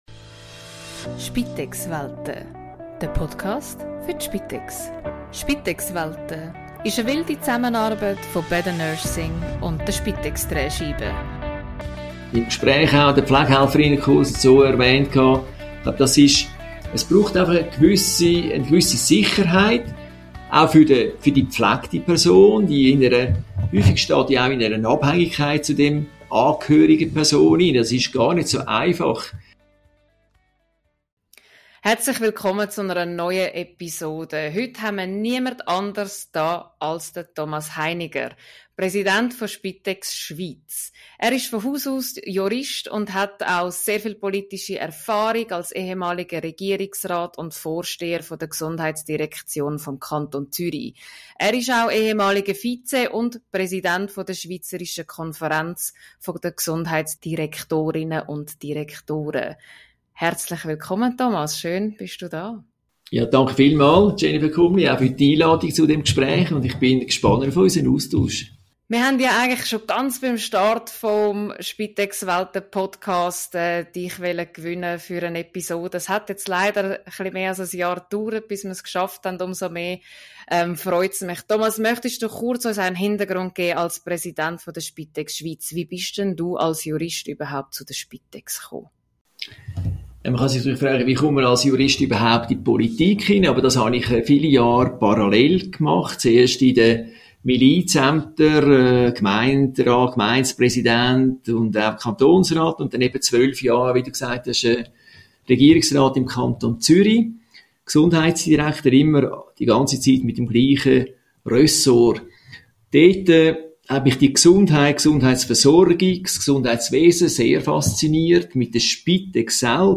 Gast: Thomas Heiniger, Präsident Spitex Schweiz Host